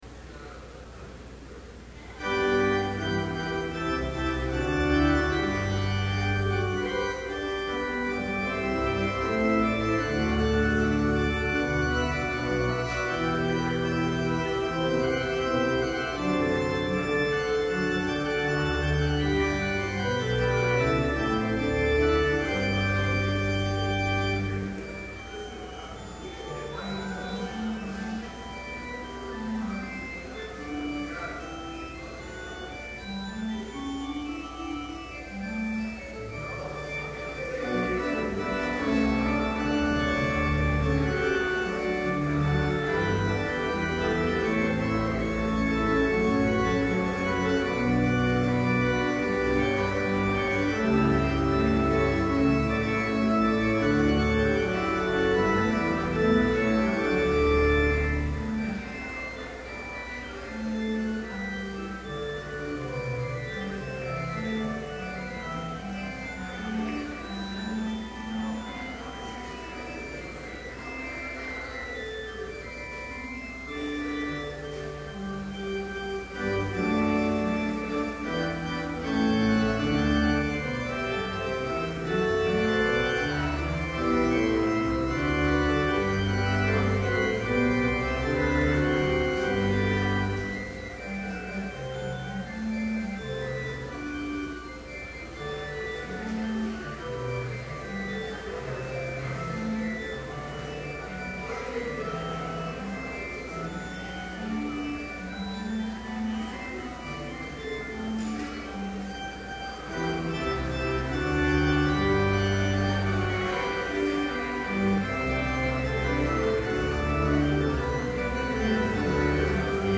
Complete service audio for Chapel - January 19, 2012
Prelude Hymn 125, vv. 2 & 4, Christ, by highest heaven adored... Scripture Reading: Joshua 24:14-15 Homily Prayer Hymn 189, In House and Home Benediction Postlude